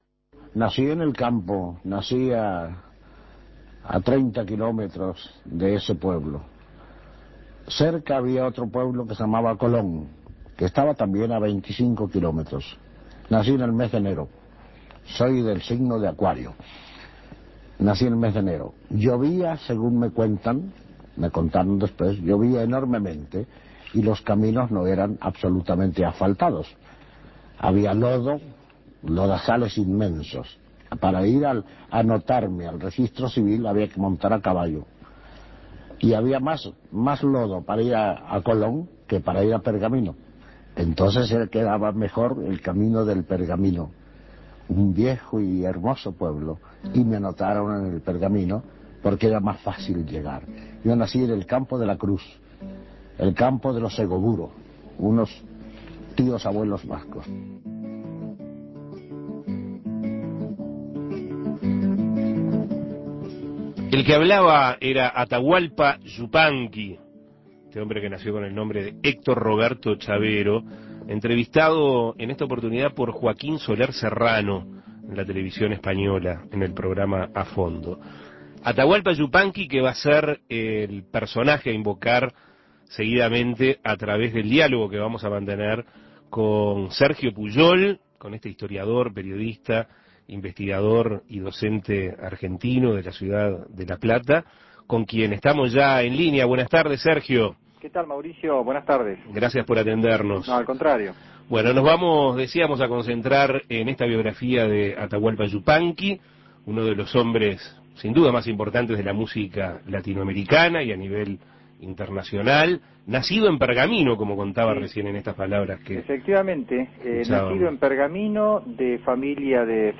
Entrevistas Atahualpa Yupanqui